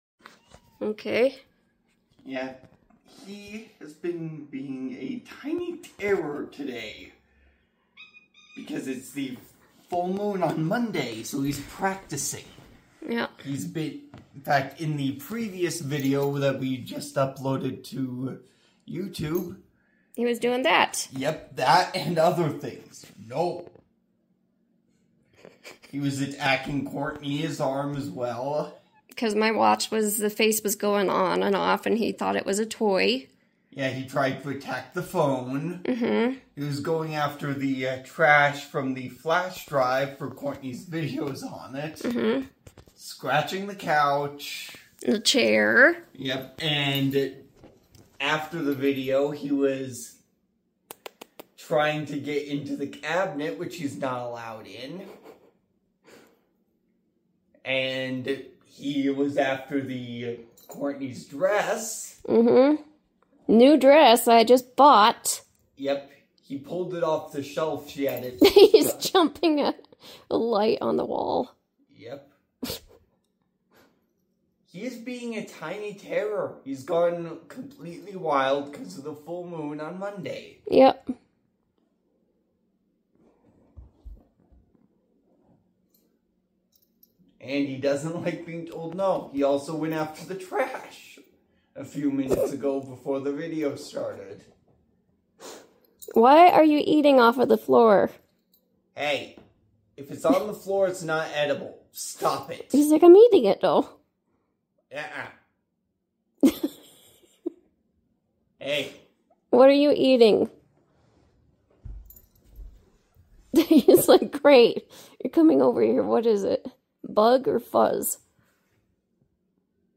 A video of my cat Chomusuke